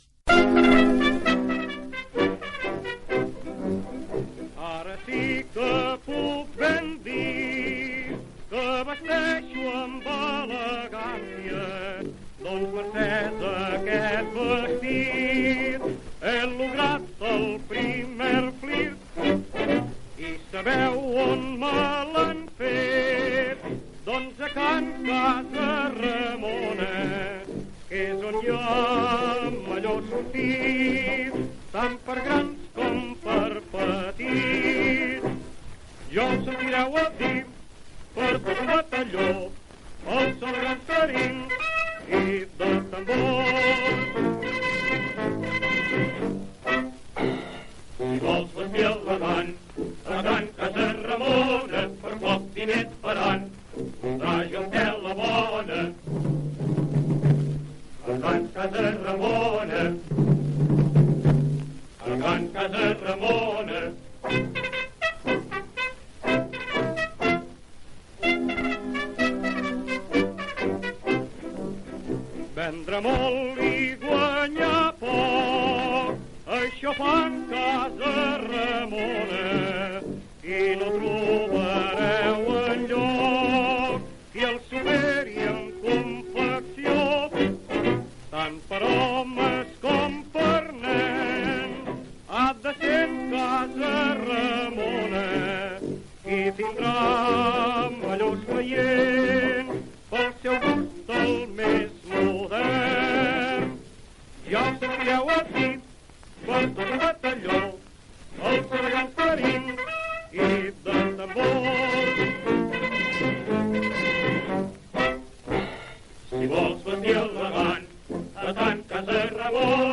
Cançó publicitària